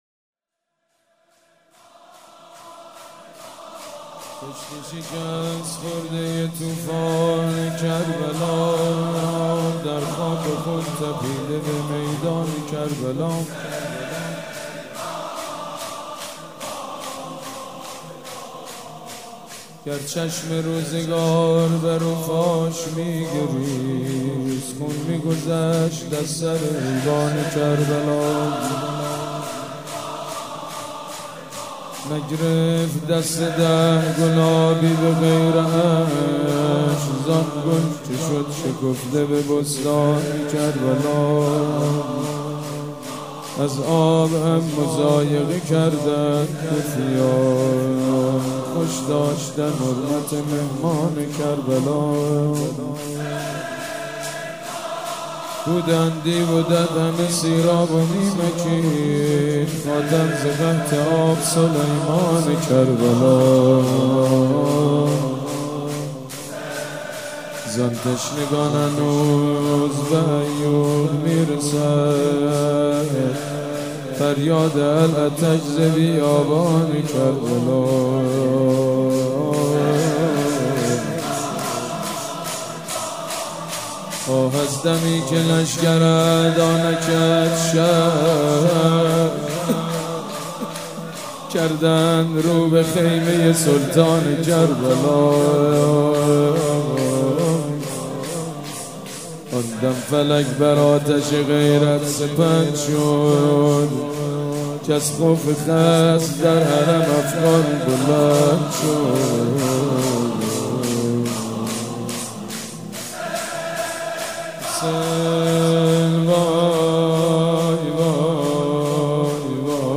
شب دوم محرم الحرام‌ دوشنبه ۱۲ مهرماه ۱۳۹۵ هيئت ريحانة الحسين(س)
سبک اثــر شور مداح حاج سید مجید بنی فاطمه
مراسم عزاداری شب دوم